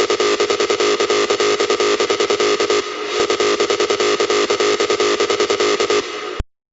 Fast Techno Bassline
fast-techno-bass-line-uptempo_150bpm_G.wav